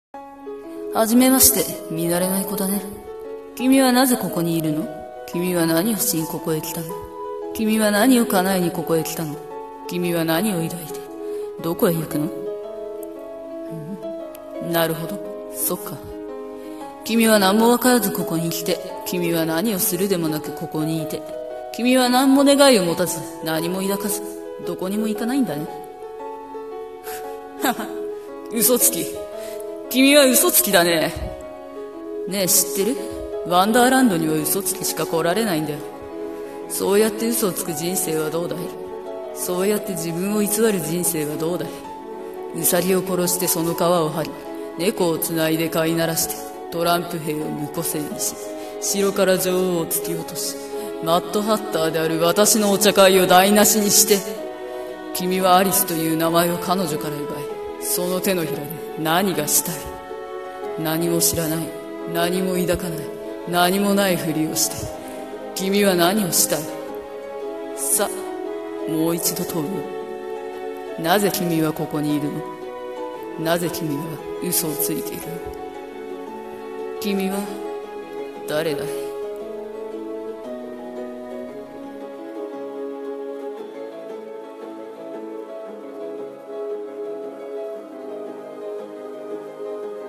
声劇台本 帽子屋とアリス